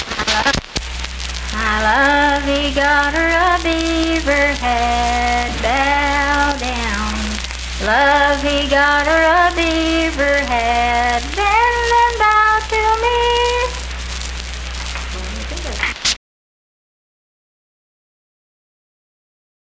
Unaccompanied vocal music
Voice (sung)
Roane County (W. Va.), Spencer (W. Va.)